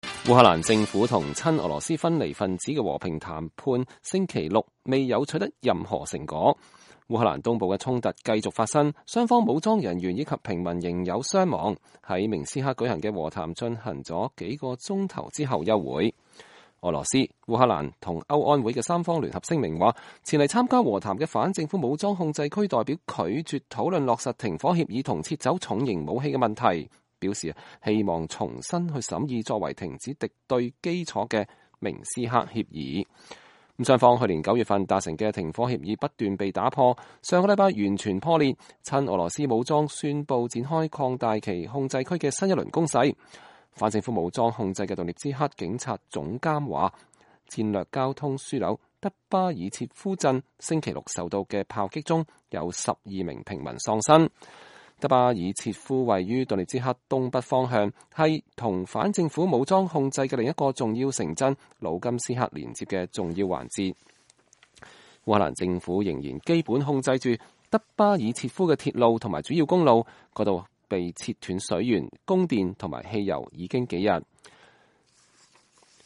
親俄羅斯武裝代表在會談後對記者講述談判經過